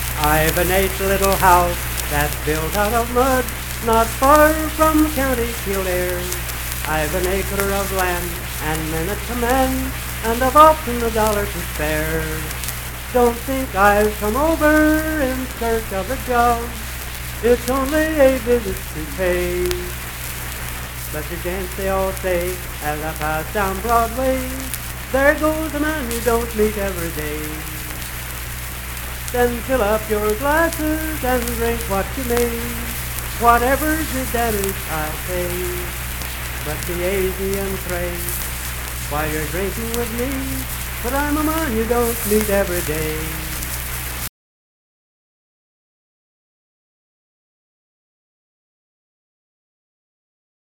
Unaccompanied vocal music
Ethnic Songs
Voice (sung)